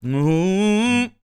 MOANIN 020.wav